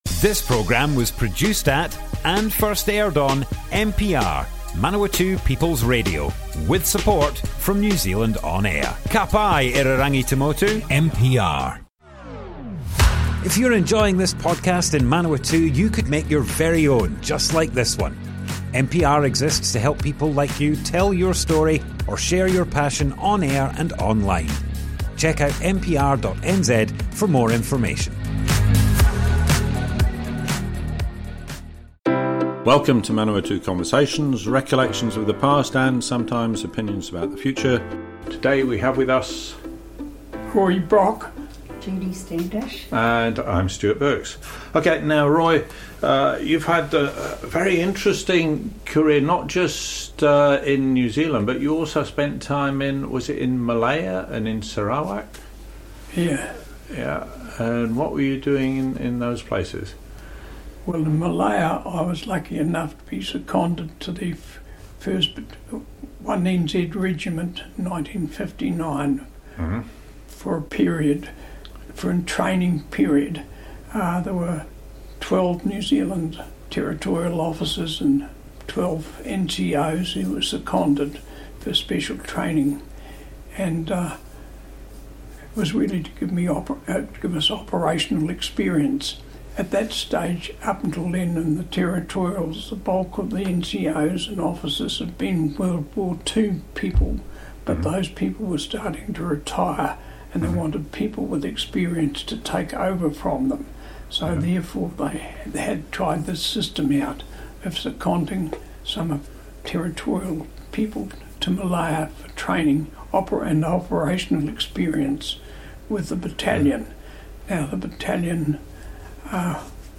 Manawatu Conversations More Info → Description Broadcast on Manawatu People's Radio, 14th February 2023.
oral history